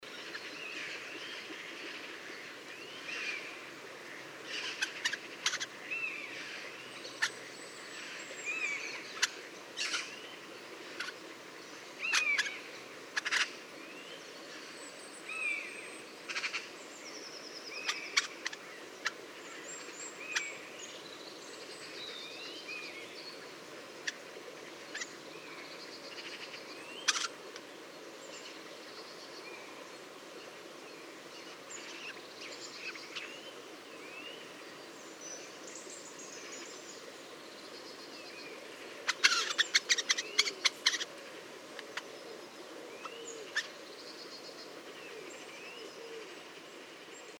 Eurasian Jay Garrulus g. glandarius, song
Crested Lark Galerida cristata magna, song, calls